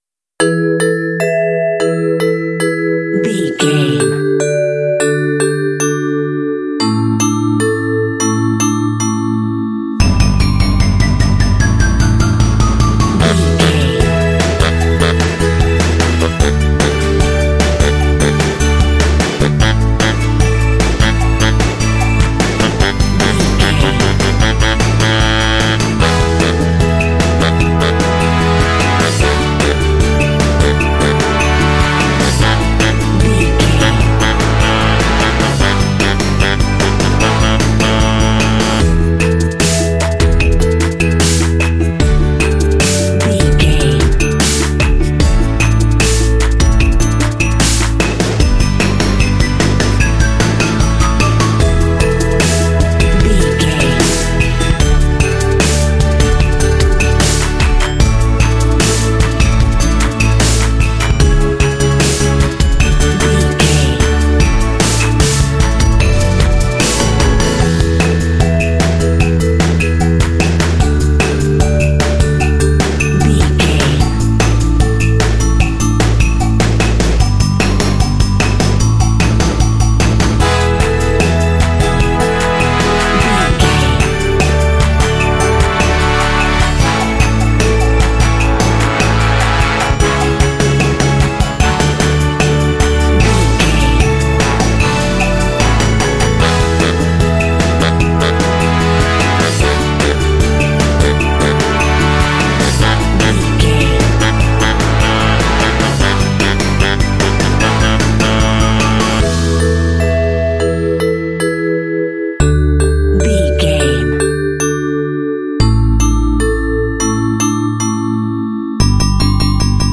Uplifting
Ionian/Major
Fast
energetic
electric piano
drums
synthesiser
saxophone
percussion
playful
pop
contemporary underscore